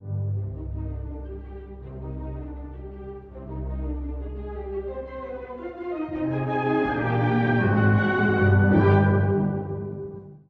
第一主題は、剣を振りかざして突進するようなエネルギー。